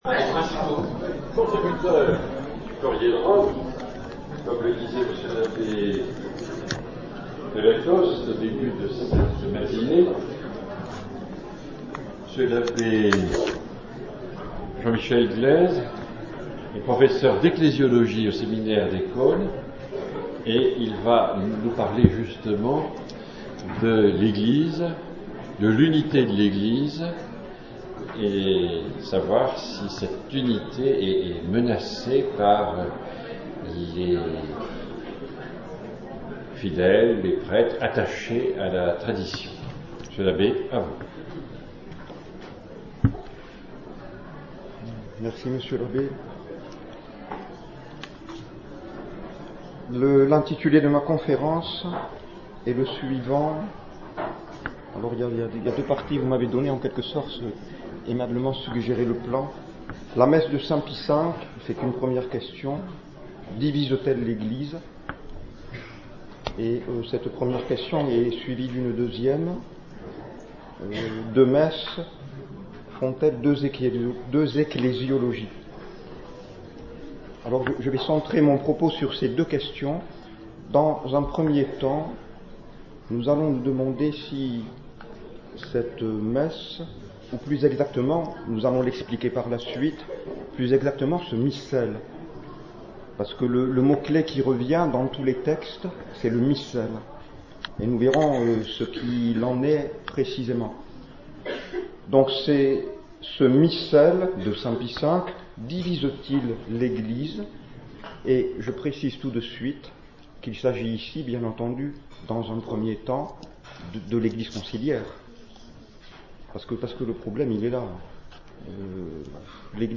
Les Conférences